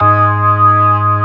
55O-ORG07-G#.wav